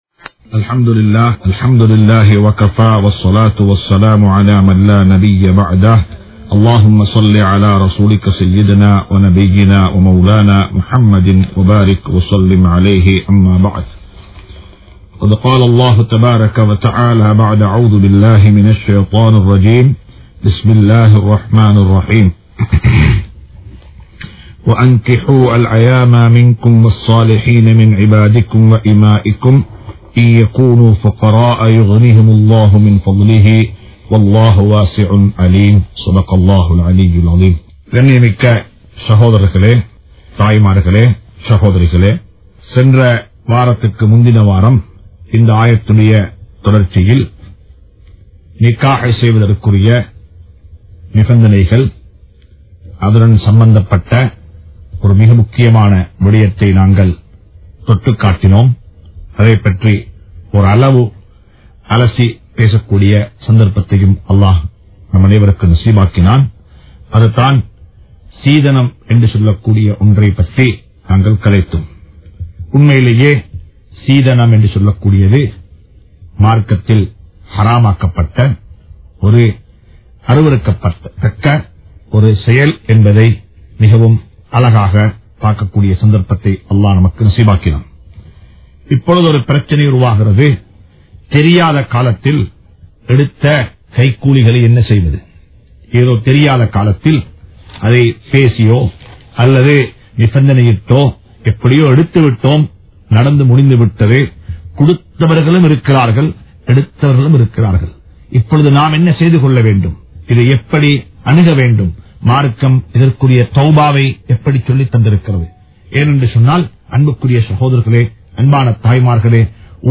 Seethanamum Em Samoohamum (சீதனமும் எம் சமூகமும்) | Audio Bayans | All Ceylon Muslim Youth Community | Addalaichenai